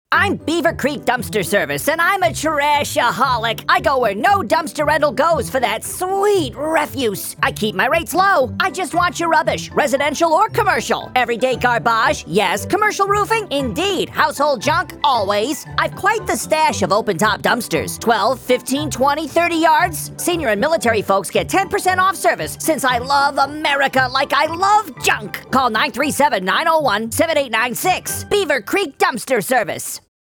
30-second Audio Ad
The client requested a single voice script highlighting their offerings and if possible, incorporating their logo/mascot—a beaver. They mentioned a preference for dry humor.
Cartoonish beaver voice. Think Pepe Le Pew meets Bullwinkle. He speaks with a lot of passion, emphasizing every trash-related word.